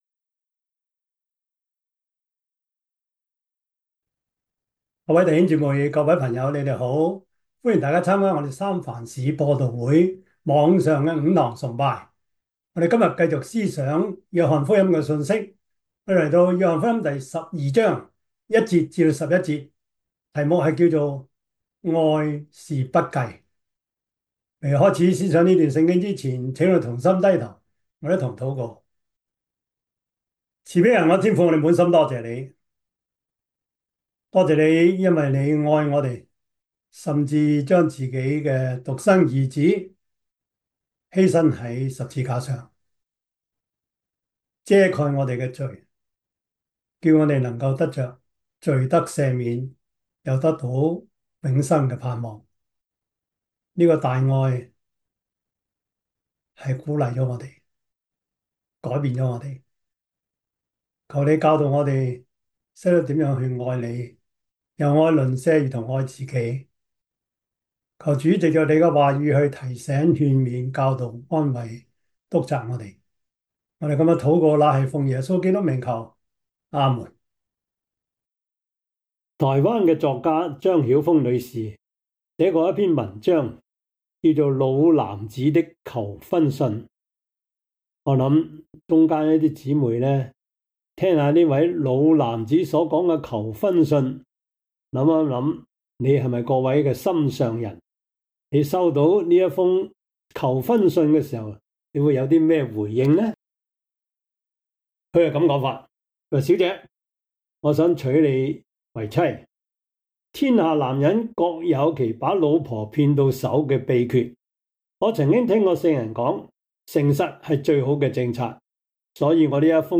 約翰福音 12:1-11 Service Type: 主日崇拜 約翰福音 12:1-11 Chinese Union Version
Topics: 主日證道 « 關懷是互相代求 認識精神病及情緒病 – 02 »